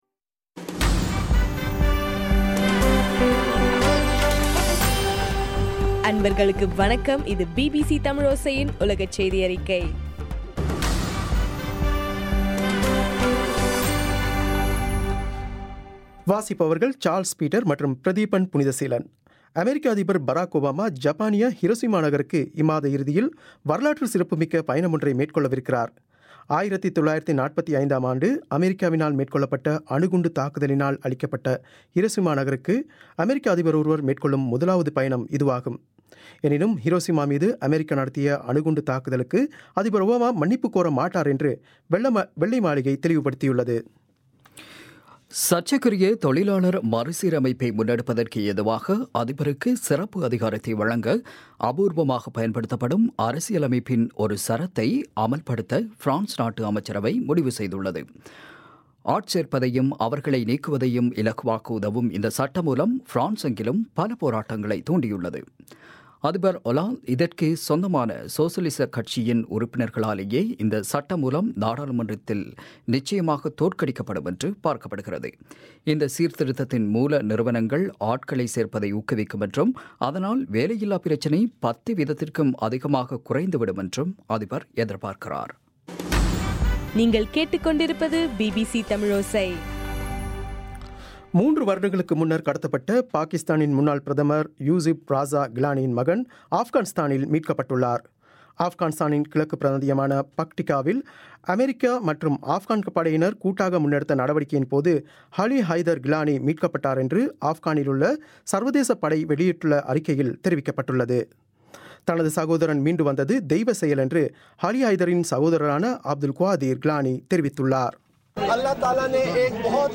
மே 10 பிபிசியின் உலகச் செய்திகள்